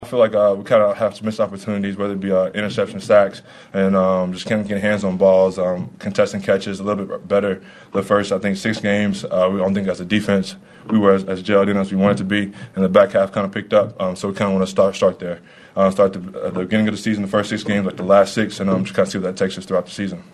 Linebacker Nick Bolton would like to see the defense pick up where it left off.
5-25-nick-bolton.mp3